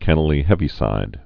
(kĕnə-lē-hĕvē-sīd)